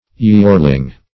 yeorling - definition of yeorling - synonyms, pronunciation, spelling from Free Dictionary Search Result for " yeorling" : The Collaborative International Dictionary of English v.0.48: Yeorling \Yeor"ling\, n. [Cf. Yellow .]